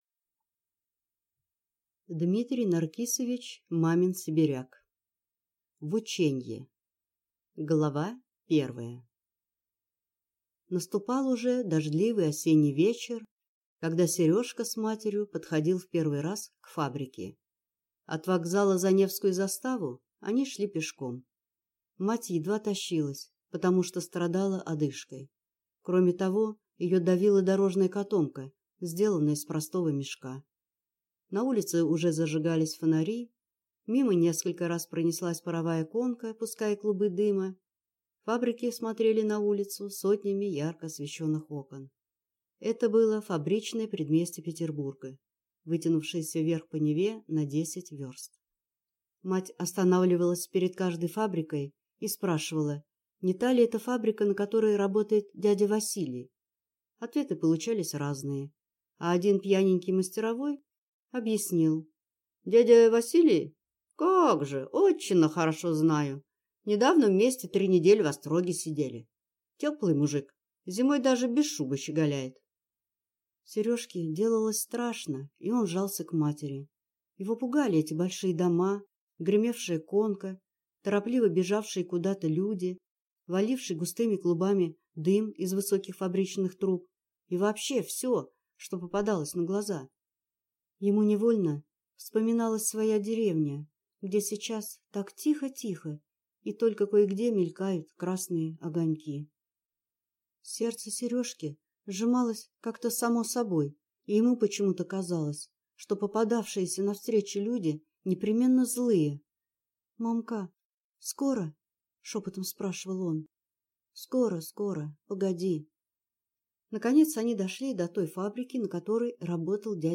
Аудиокнига В ученье | Библиотека аудиокниг